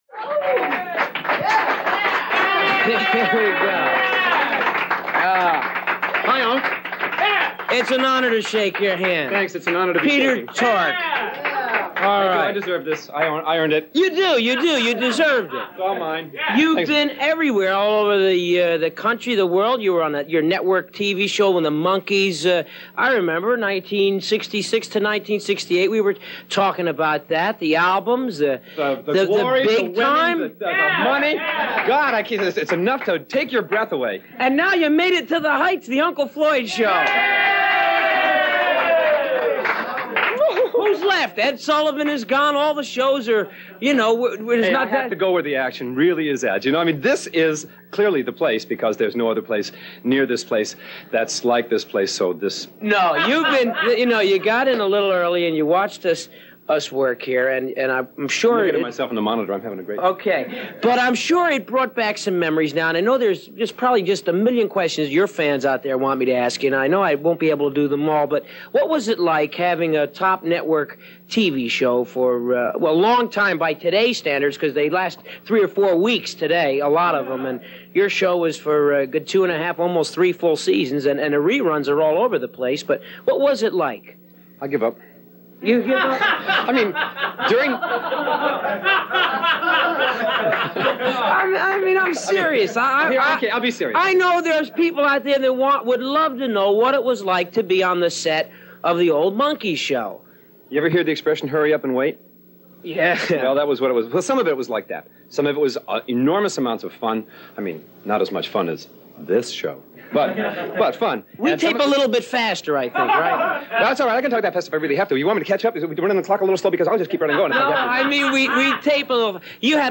Peter Tork – Way of Tao 1 13 Interview